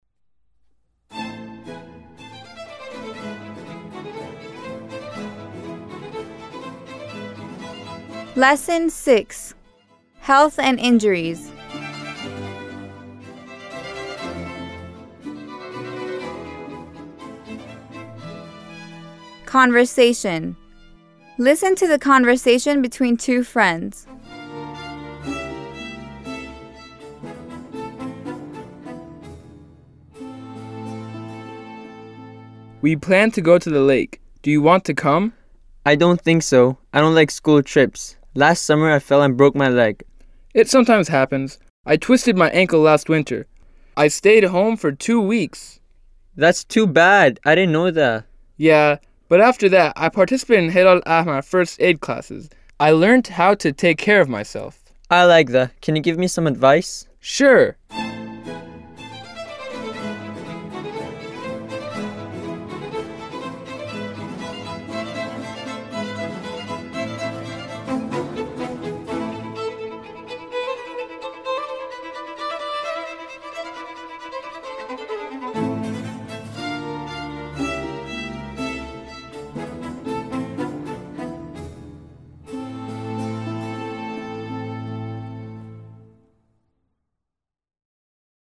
9-L6-Conversation
9-L6-Conversation.mp3